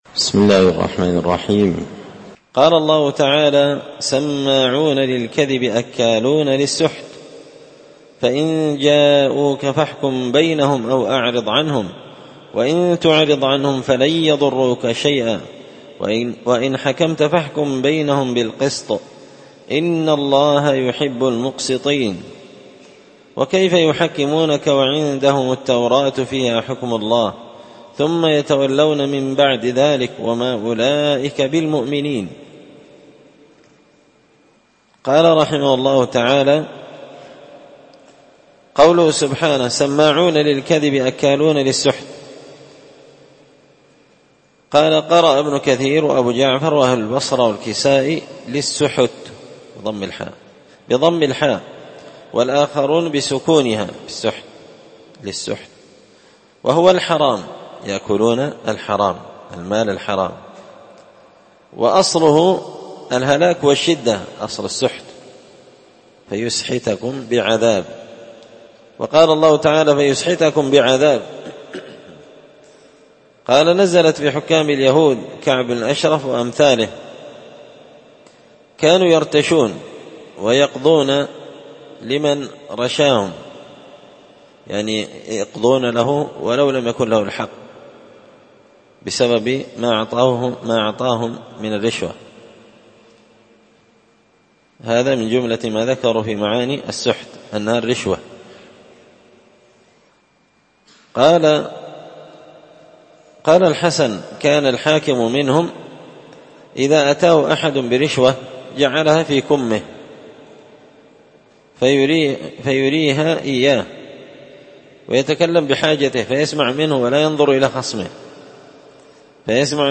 مختصر تفسير الإمام البغوي رحمه الله الدرس 254